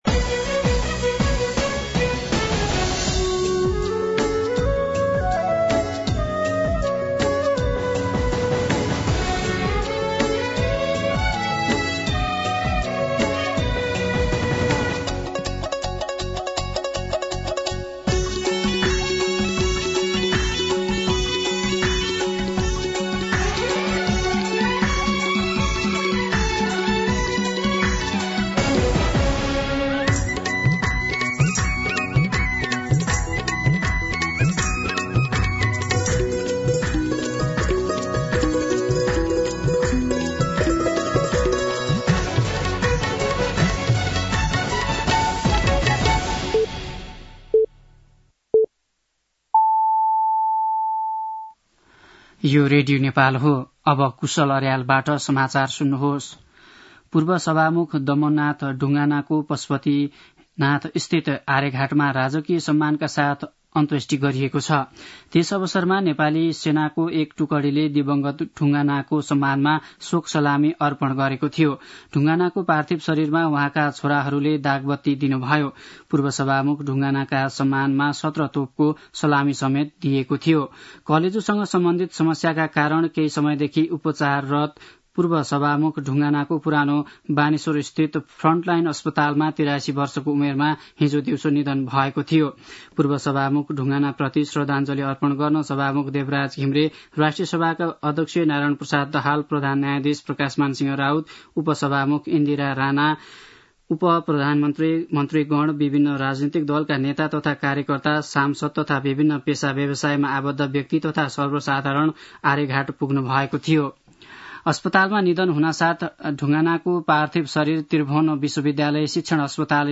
दिउँसो ४ बजेको नेपाली समाचार : ४ मंसिर , २०८१
4-pm-nepali-news-.mp3